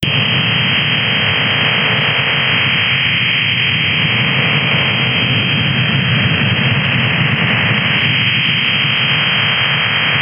Orthogonal frequency division multiplexing (OFDM) is a transmission technique that consists of the multiplexing of a set of carrier waves of different frequencies, where each one carries information, which is modulated in QAM. or in PSK.
This CIS MIL mode is also known as Russian High Data Rate modem
Modulation: OFDM
Bandwidth: 2.8 kHz
Baud rate = 60 x 30 Bd